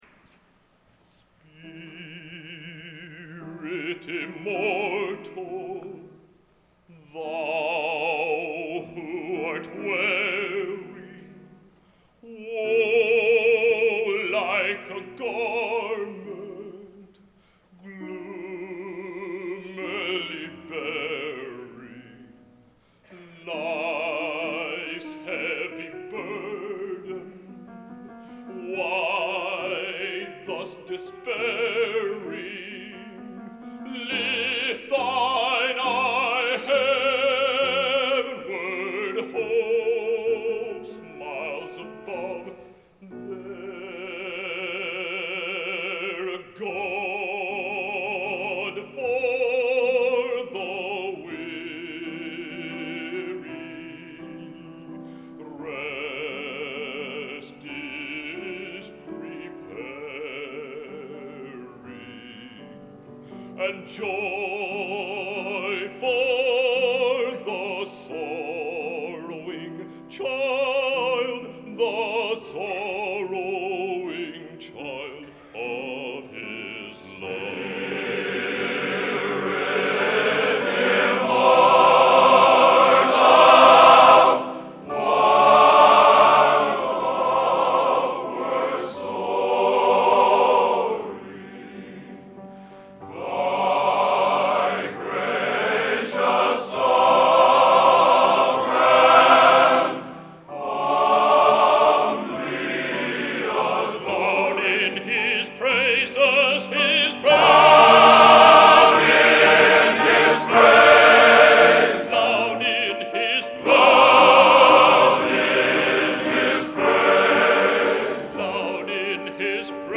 Genre: Opera | Type: Solo